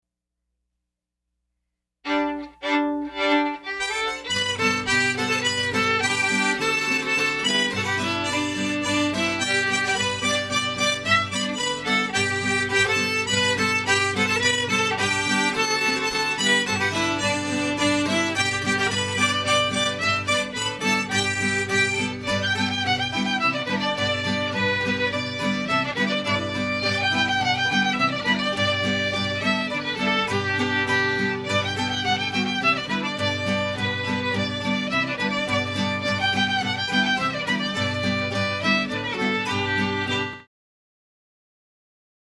Dark of the Moon, a women's band based in Madison, Wisconsin, plays traditional music from the the British Isles, America, Scandinavia, and other countries for dancing and listening. We specialize in music for contradancing, such as jigs and reels, and also play waltzes, polkas, schottisches, folk songs, Cajun, bluegrass, Klezmer, Mexican, and other traditional music.
Six women with instruments